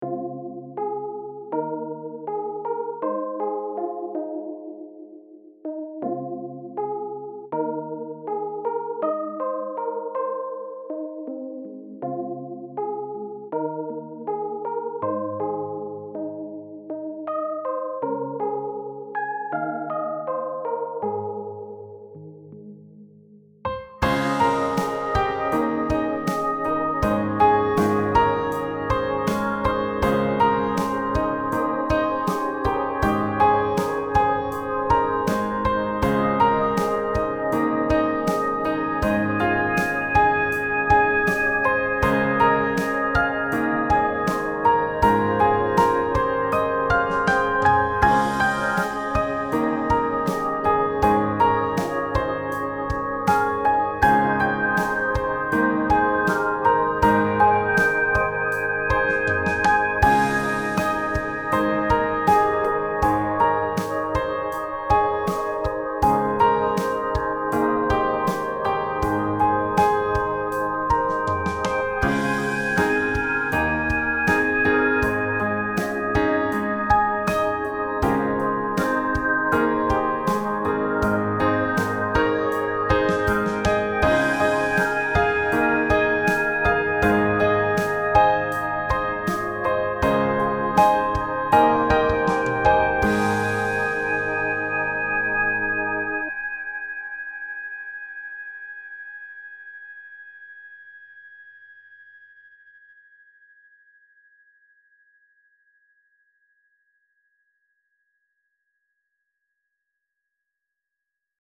未分類 どこか懐かしい夏 ピアノ 幻想的 懐かしい 浸る夜 音楽日記 よかったらシェアしてね！